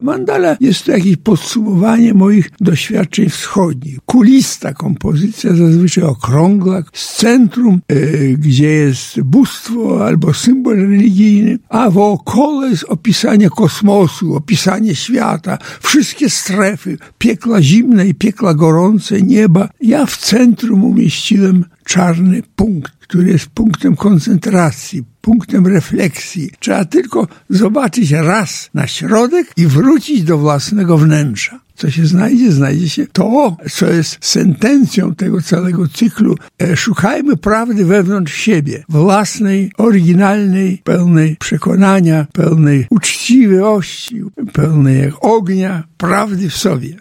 Jak oglądać wystawę? Instrukcji udziela sam artysta.